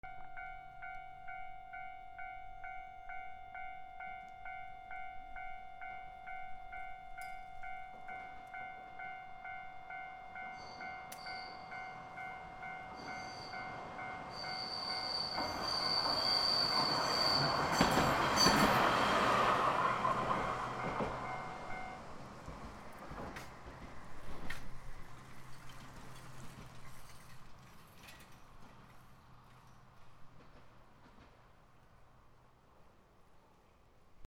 電車通過(踏み切り)
/ E｜乗り物 / E-60 ｜電車・駅